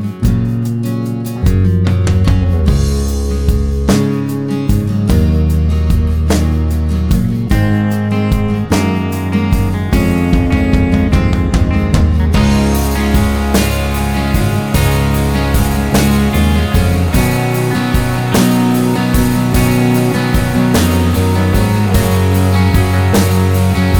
Backing tracks for male or boy singing parts.